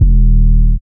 REDD 808 (15).wav